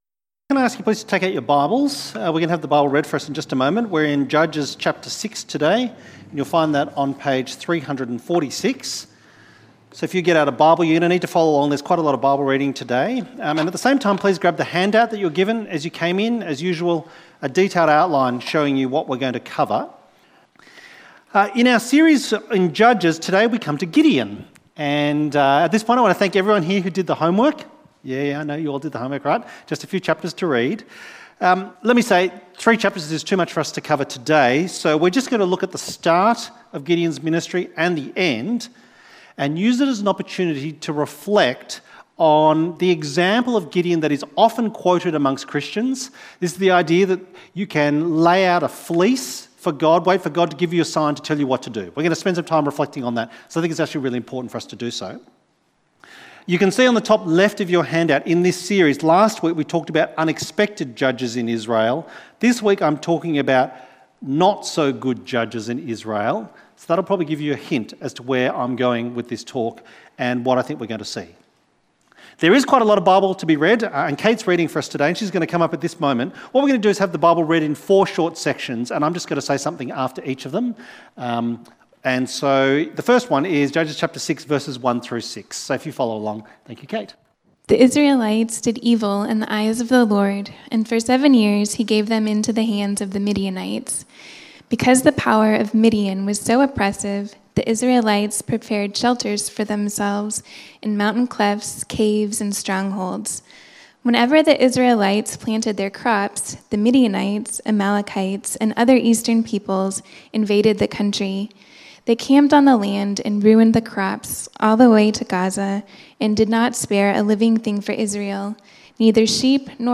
Not-So-Good Judges in Israel Sermon outline Share this page